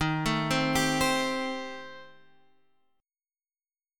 D#6b5 chord